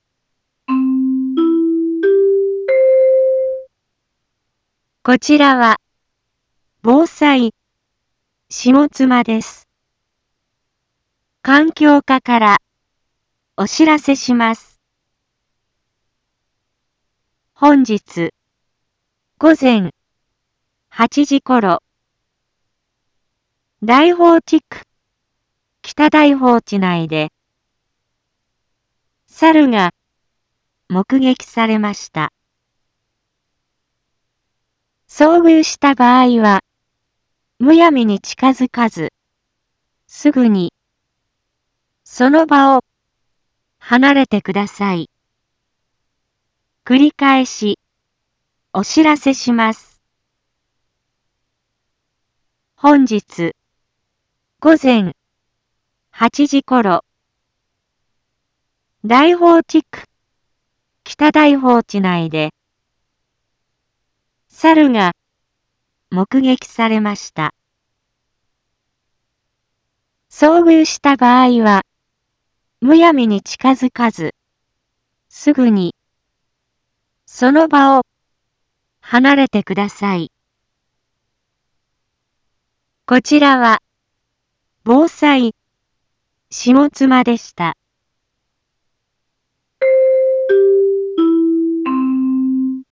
一般放送情報
Back Home 一般放送情報 音声放送 再生 一般放送情報 登録日時：2024-09-25 10:01:38 タイトル：サルの目撃情報について インフォメーション：こちらは、ぼうさいしもつまです。